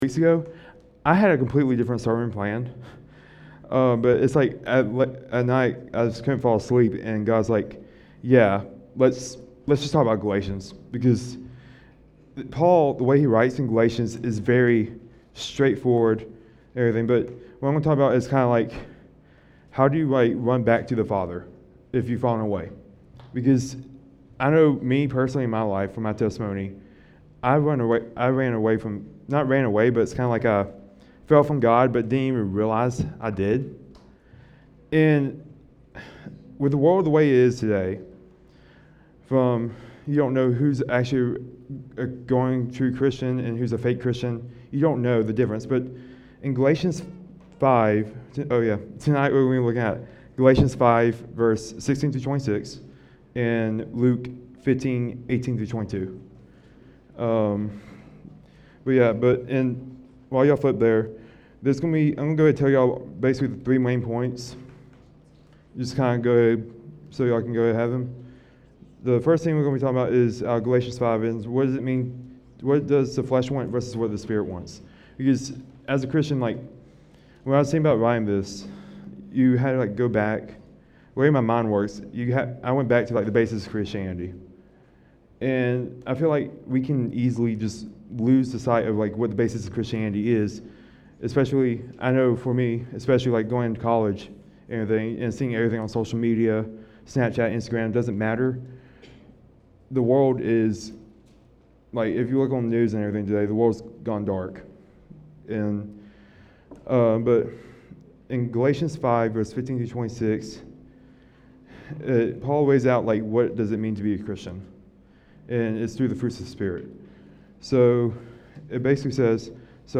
Jacob Sermon